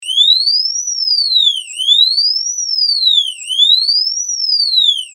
SIRENA 8 TONOS
Sirena con 8 tonos diferentes
Tono_8